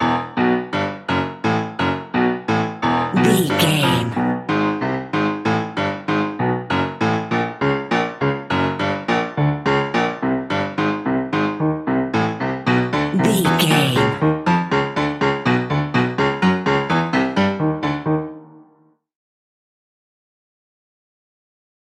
In-crescendo
Thriller
Aeolian/Minor
scary
ominous
dark
suspense
eerie
piano
percussion
synths
atmospheres